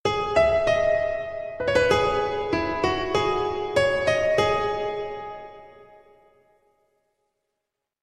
• Качество: 128, Stereo
Красивая мелодия на пианино